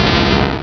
pokeemerald / sound / direct_sound_samples / cries / sandslash.aif